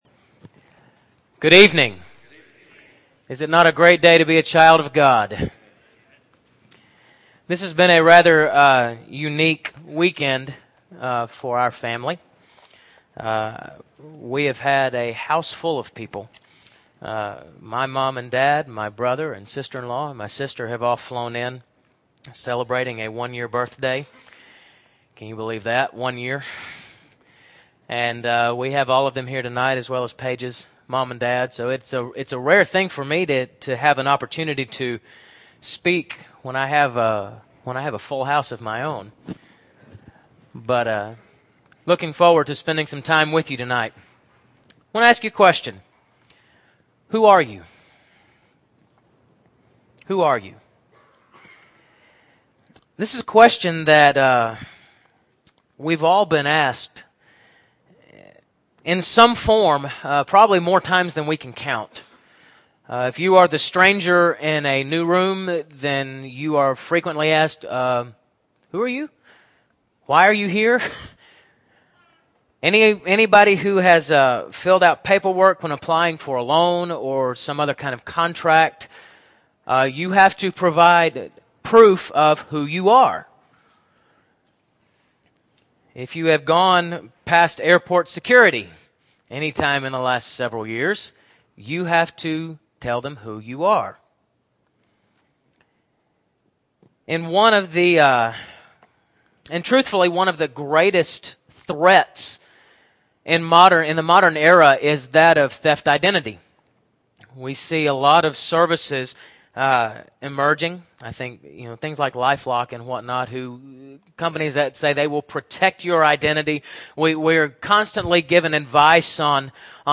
2008-08-31 – Sunday PM Sermon – Bible Lesson Recording